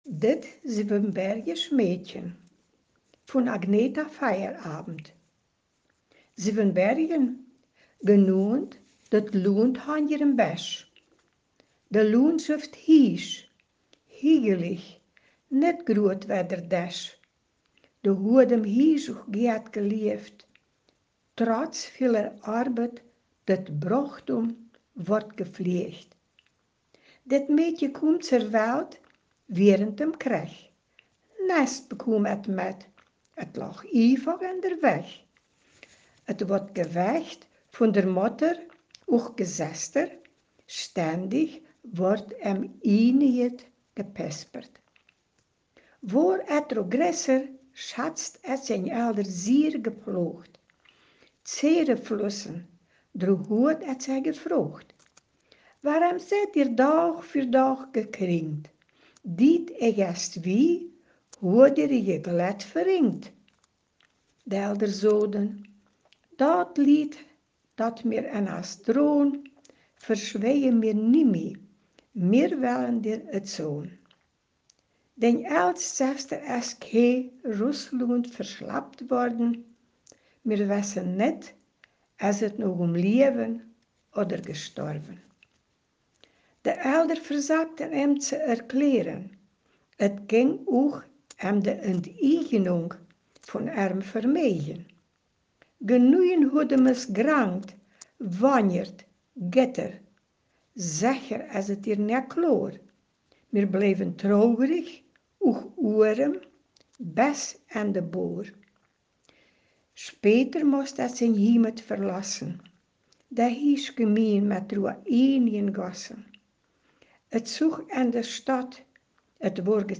Ortsmundart: Hamlesch